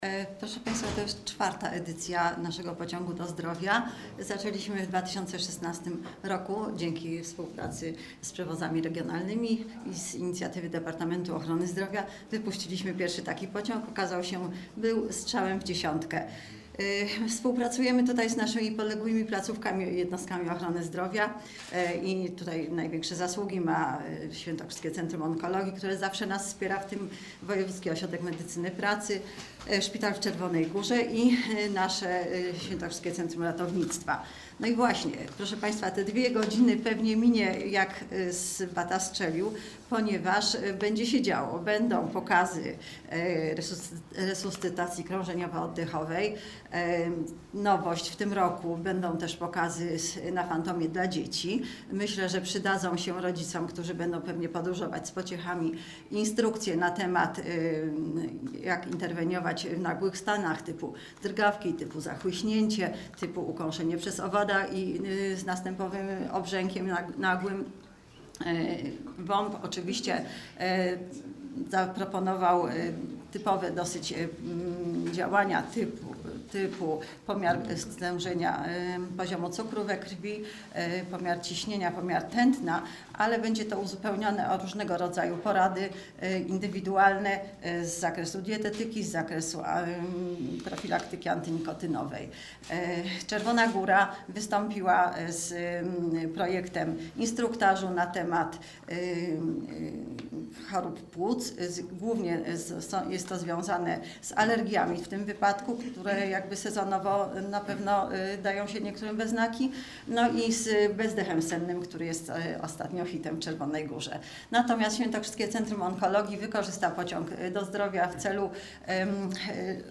Briefing prasowy na temat pociągu do Sandomierza